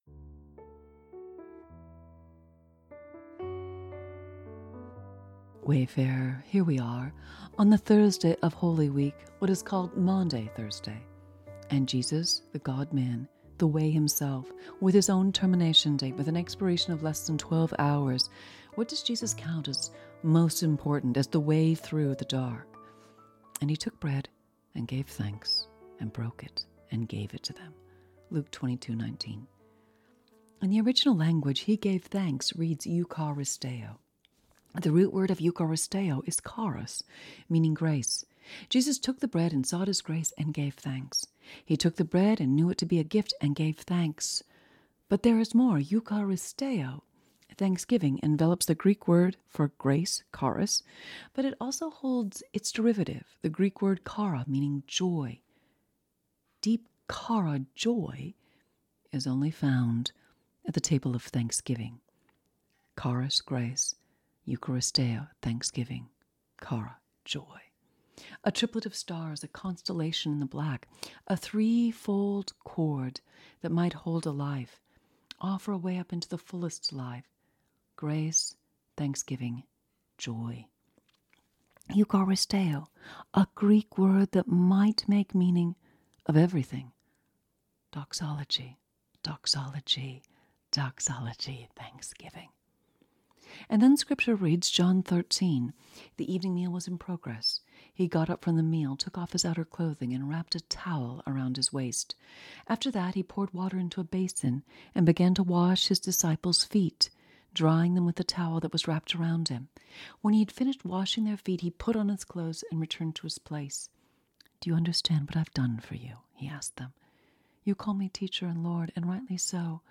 the beautiful piano melodies to our journey